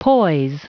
Prononciation du mot poise en anglais (fichier audio)
Prononciation du mot : poise